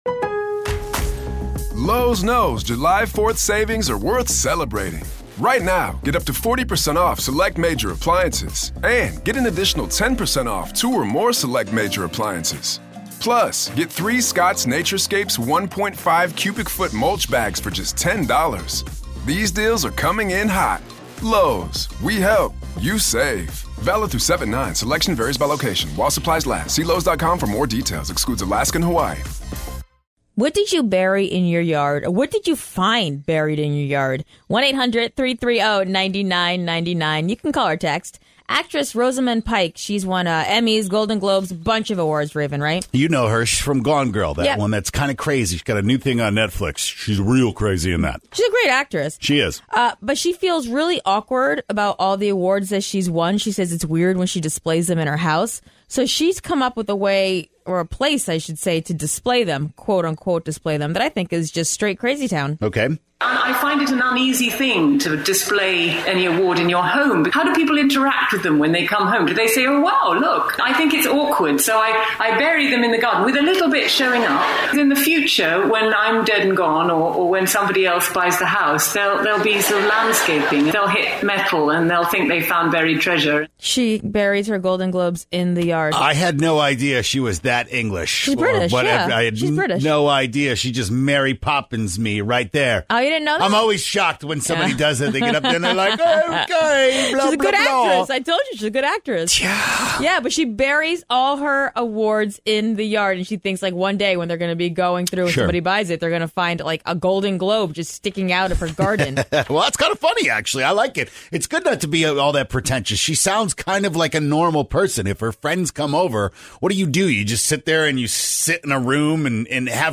But a few callers expectations might just be a little too much!